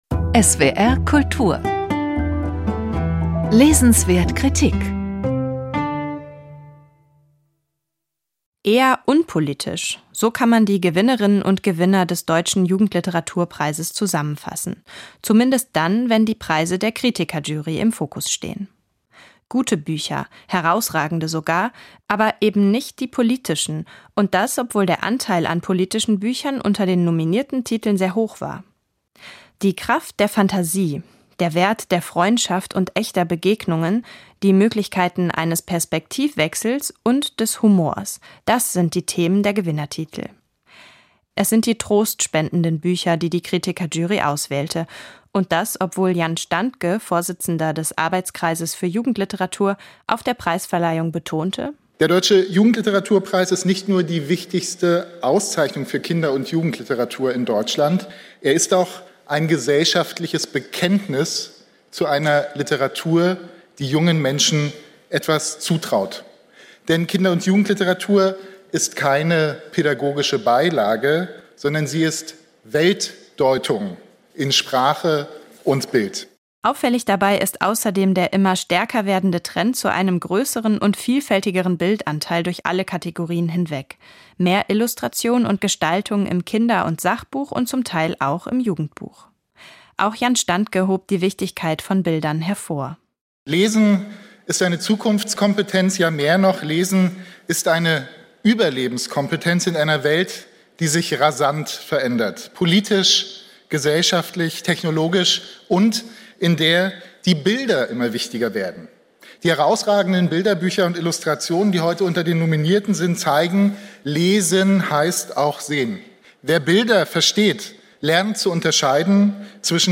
Ein Bericht